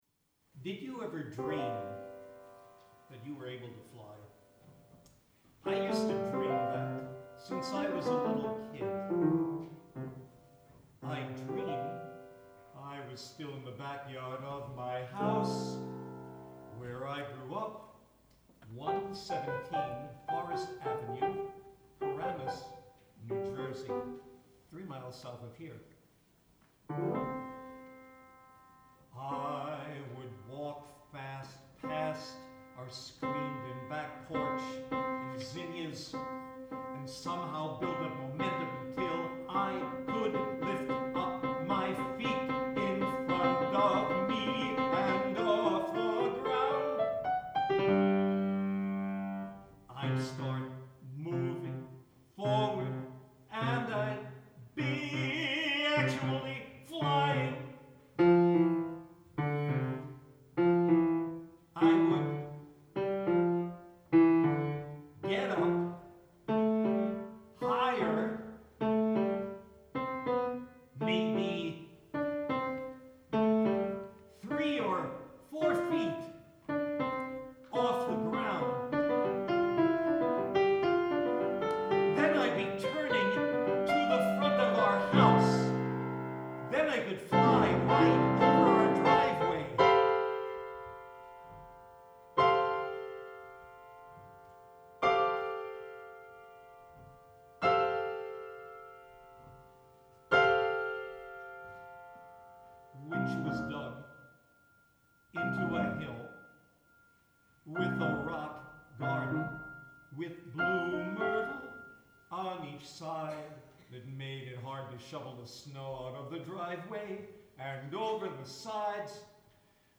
voice & piano 7 min.